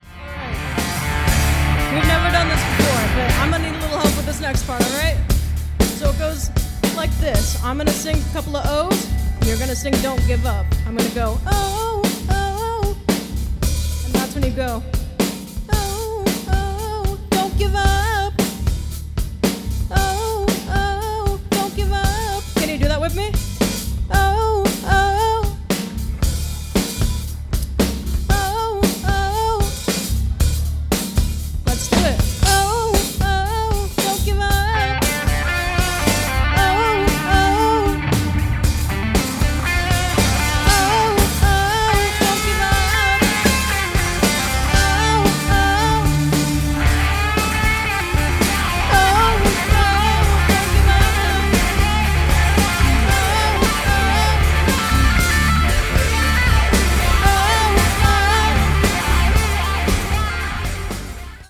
A hard hitting rock cover band
Lead Vocals
Lead Guitar, "Easter Eggs"
Bass Guitar, Occasional Vocals
Drums, Other Noises